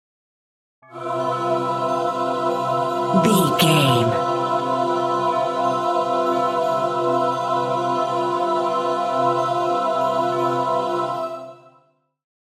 Angels Choir
Sound Effects
Atonal
dreamy
bright
calm